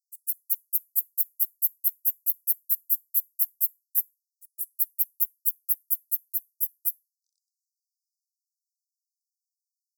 forest home shieldback
Aglaothorax hulodomus Cole, Weissman, and Lightfoot 2025
10 s of calling song and waveform. San Bernardino County, California; 22.8°C. JCR130731_02.